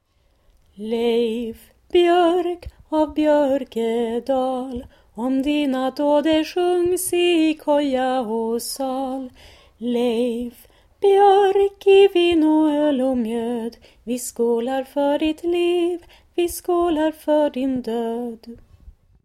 (Inspelning i dur: